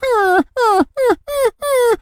pgs/Assets/Audio/Animal_Impersonations/dog_whimper_cry_03.wav at master
dog_whimper_cry_03.wav